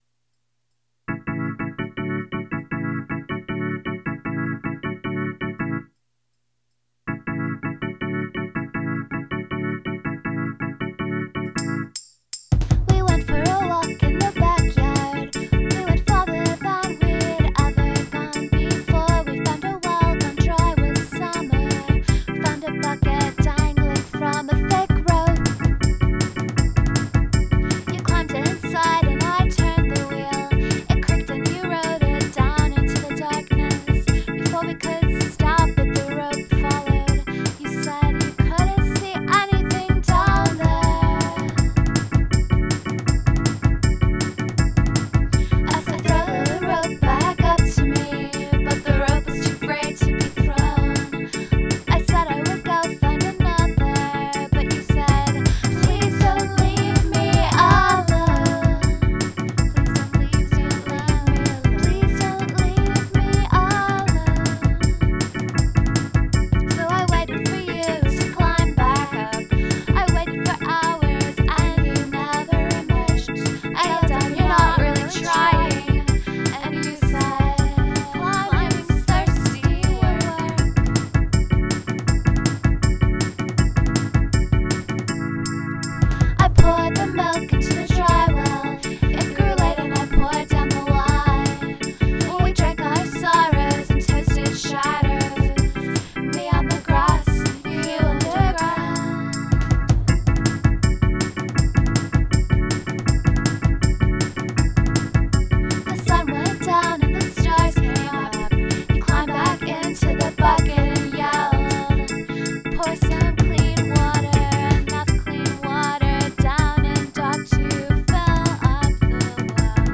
A D C G
kinda just A B over and over
the organ sound on this makes my day. good tom fills, too. the melody isn't remarkable, but it really works somehow in its repetitive way. some really good lyrics too. i like "climbing is thirsty work" and of course the twist at the end.